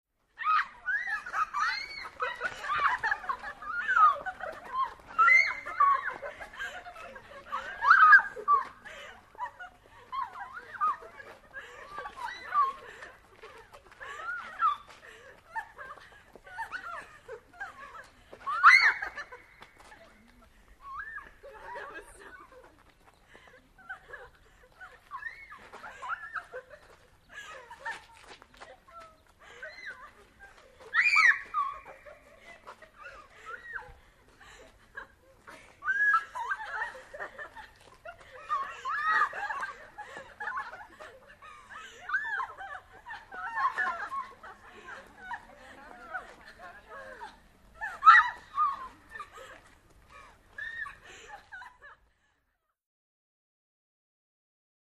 Teenage Girls Giggle And Laugh From Medium Pov.